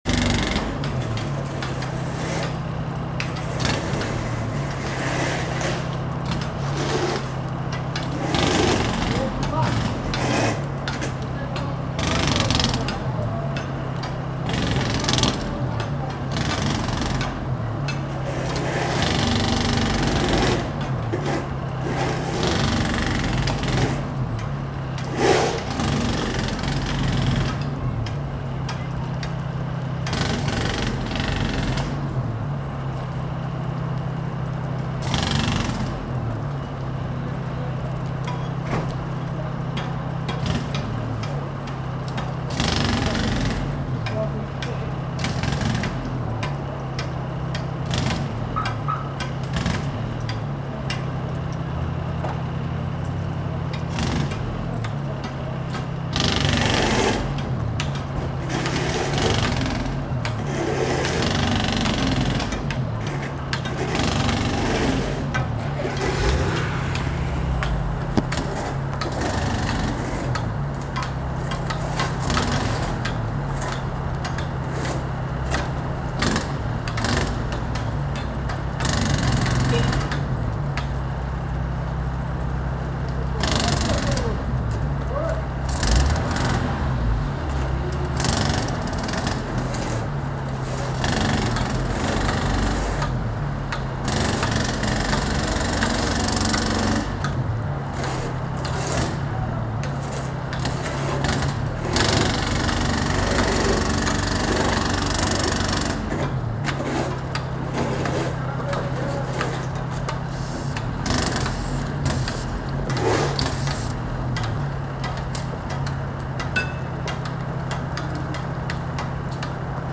Sounds of Hammer and Hammer
• Category: Hammers and hammers